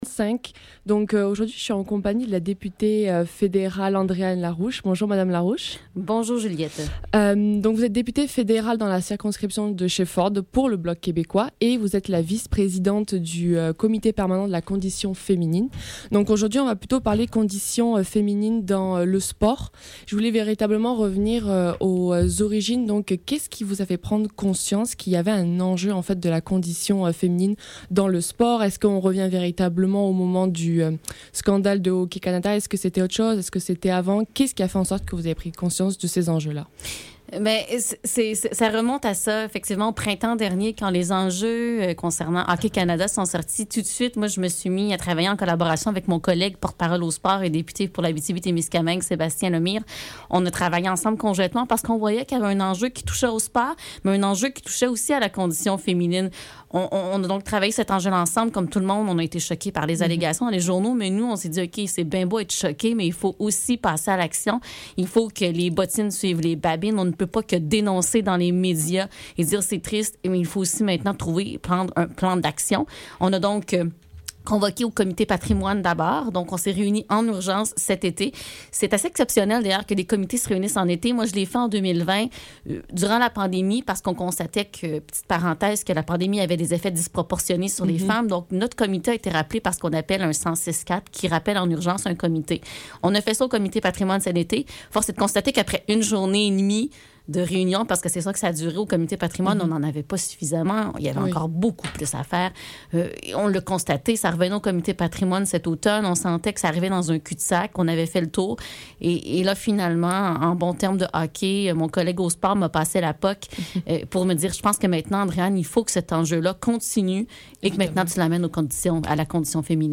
Le SEPT - Entrevue de la députée fédérale Andréanne Larouche - 21 février 2023